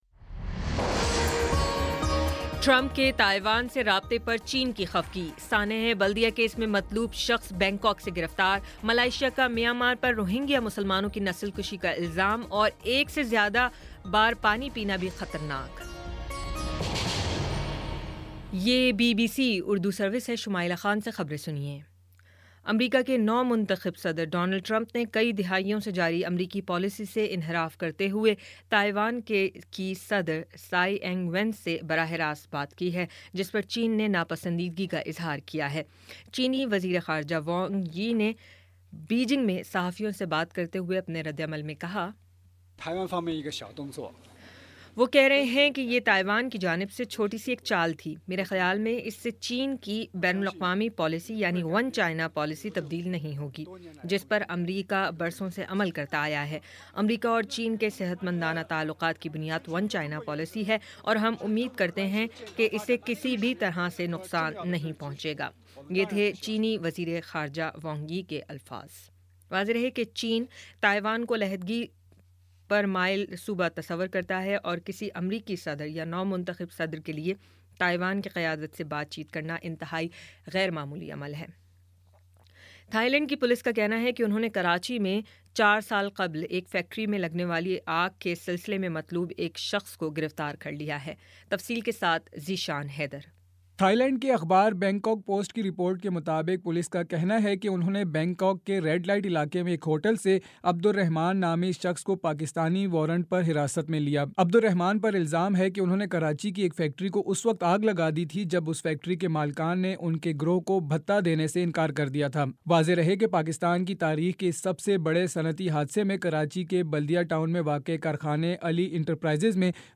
دسمبر 03 : شام پانچ بجے کا نیوز بُلیٹن